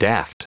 Prononciation du mot daft en anglais (fichier audio)